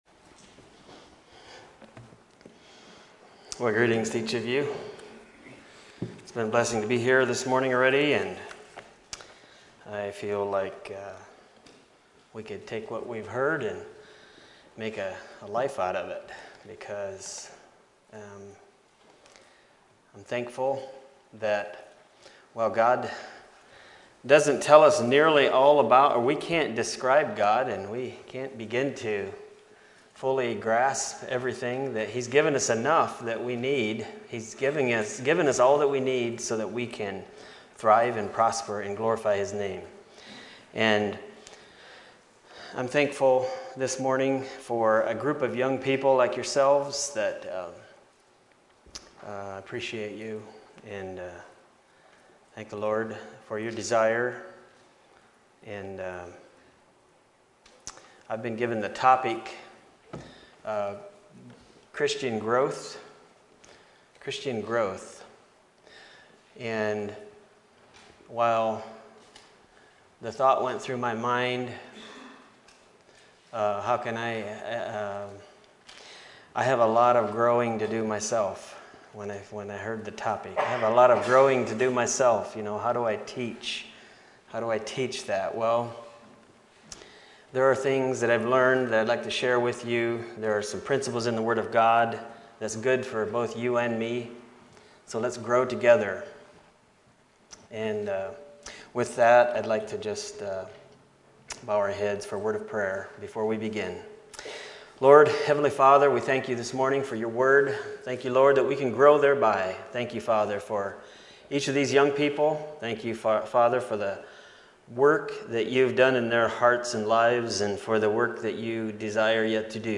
Youth Meeting Messages